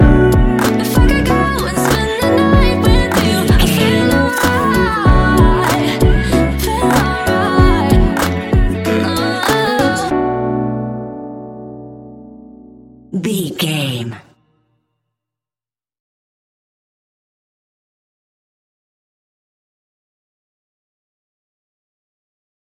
Ionian/Major
B♭
laid back
Lounge
sparse
new age
chilled electronica
ambient